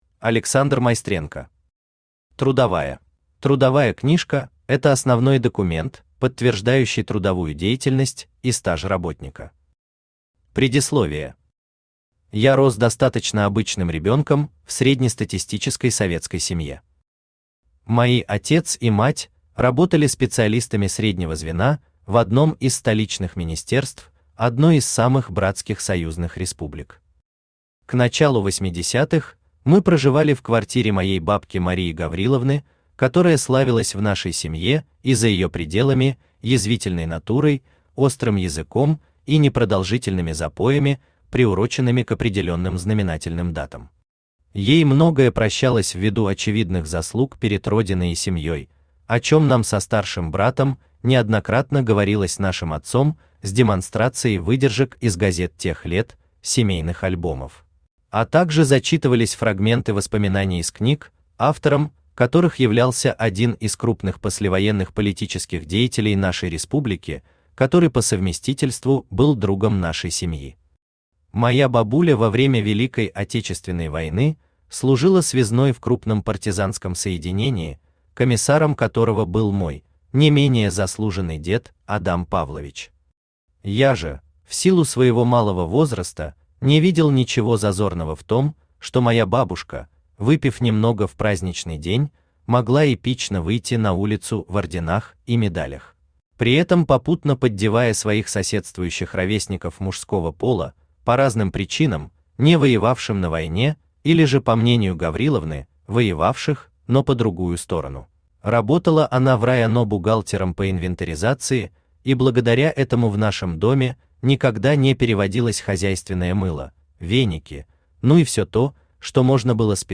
Аудиокнига Трудовая | Библиотека аудиокниг
Aудиокнига Трудовая Автор Александр Анатольевич Майстренко Читает аудиокнигу Авточтец ЛитРес.